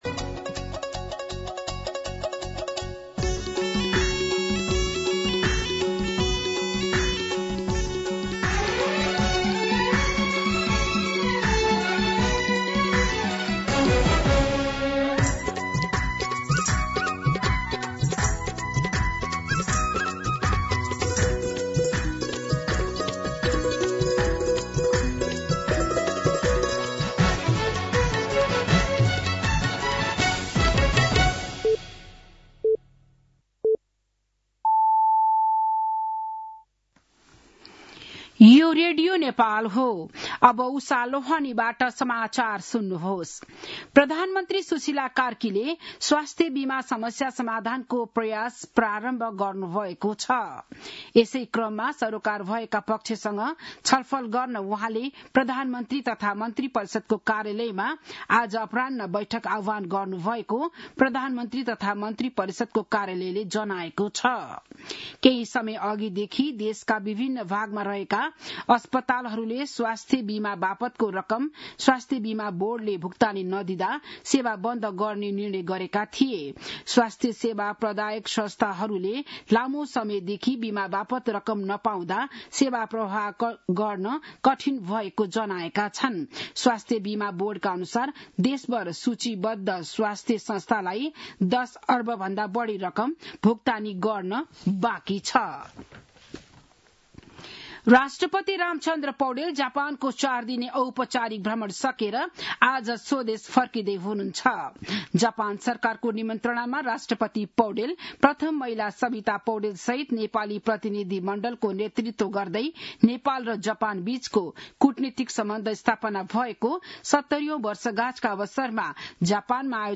बिहान ११ बजेको नेपाली समाचार : २१ माघ , २०८२
11-am-Nepali-News.mp3